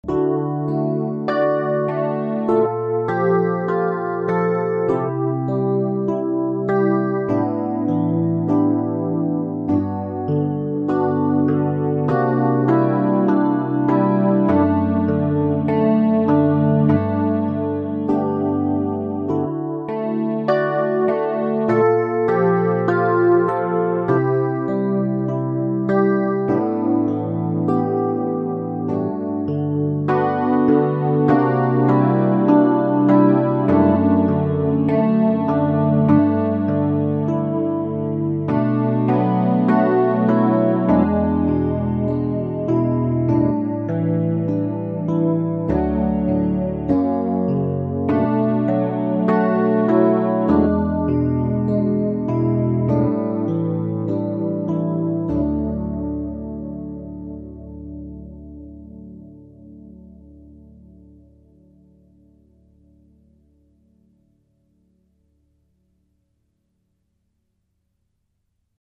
この作例ですが、データ自体はキー D ですが、
C|Ab|Bb|G7sus4
FM7|Bb7|C/E|F
これは F メジャー調のつもりです。